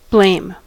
blame: Wikimedia Commons US English Pronunciations
En-us-blame.WAV